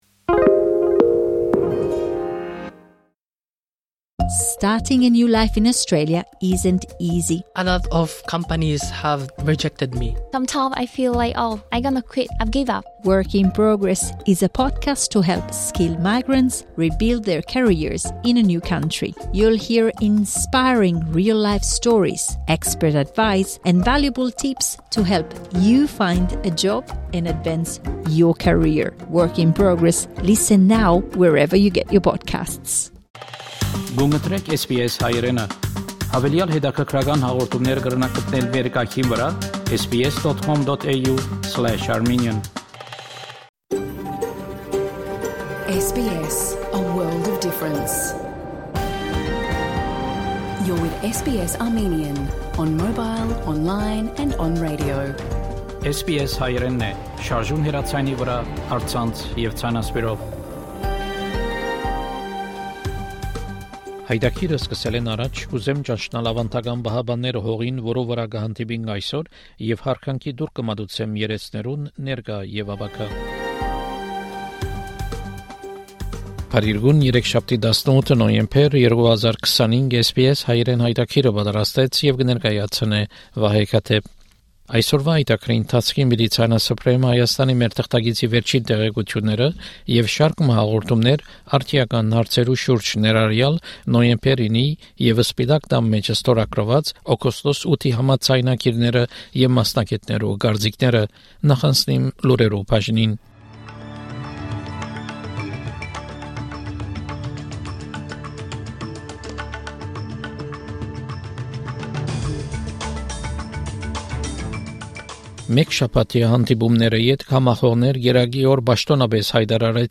SBS Armenian news bulletin from 18 November 2025 program.